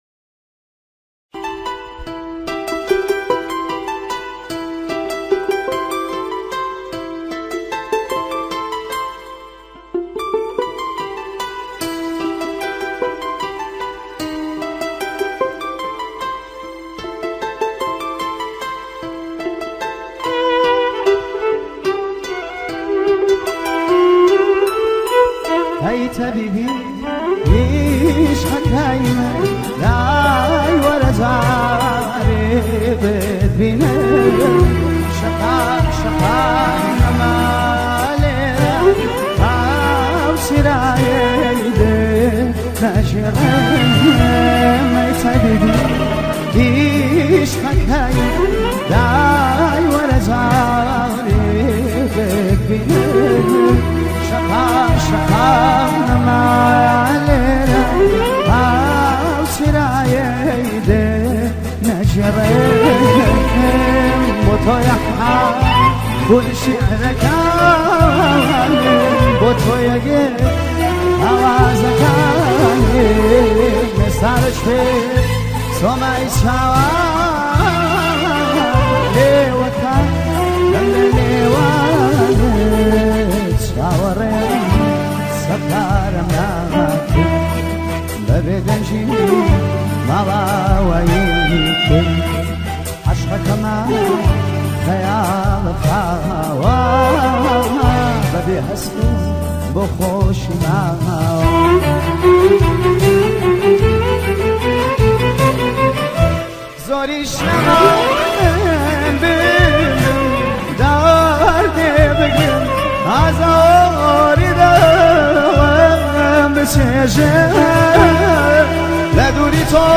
آهنگ کردی پاپ عاشقانه با کیفیت 320 عالی + پخش آنلاین